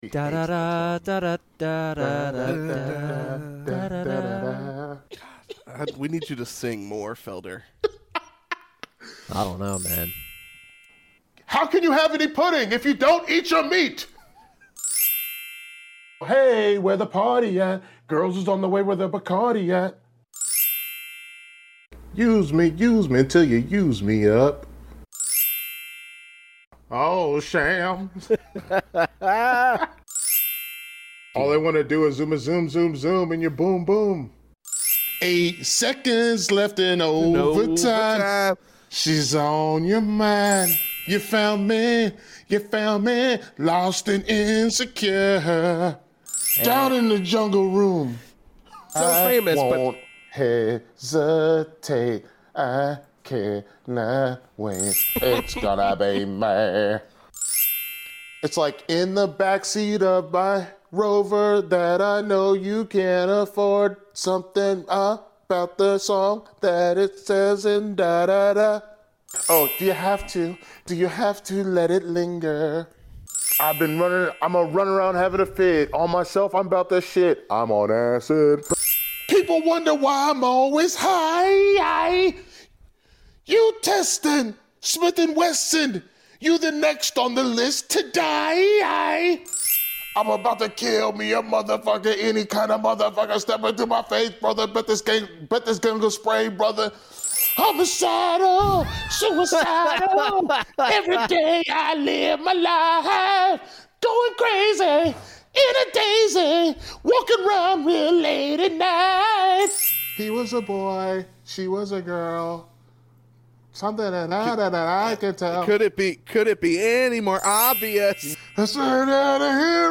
A bonus for all of y'all that have enjoyed the trip and it doesn't have the talking over it from the episode.